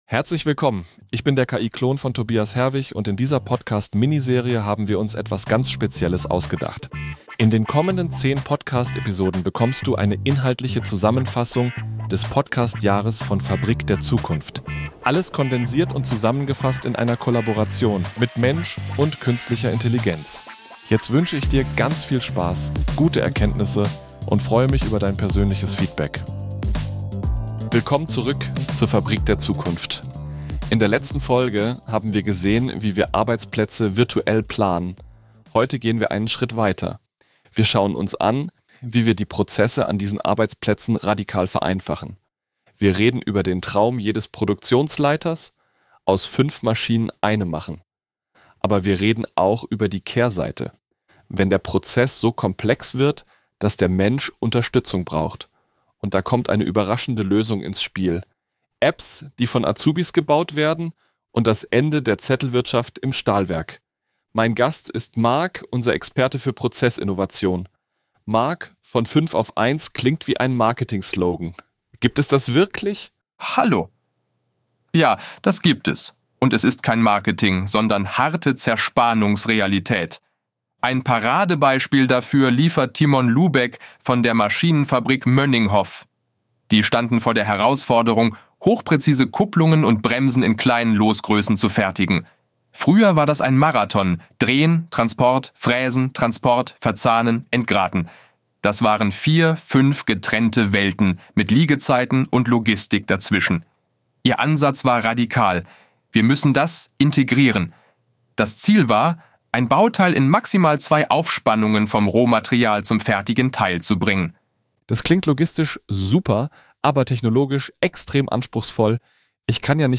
Experte für Prozessinnovation
KI‑Klon